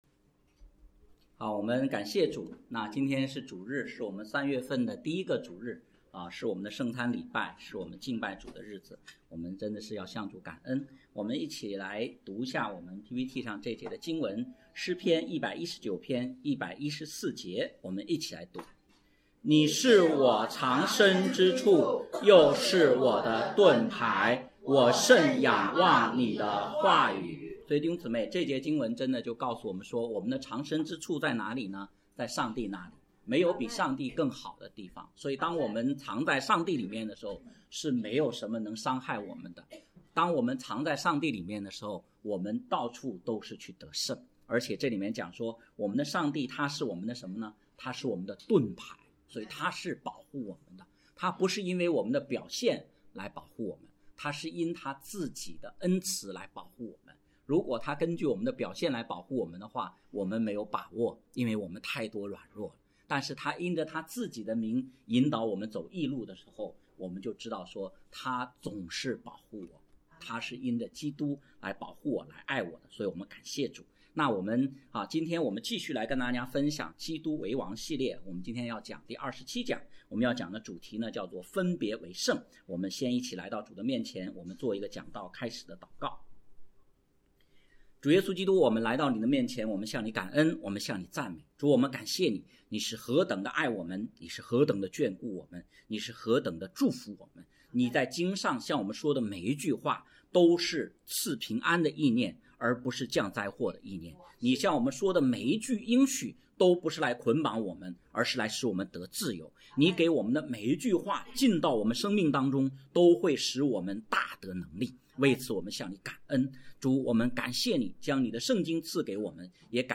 讲道录音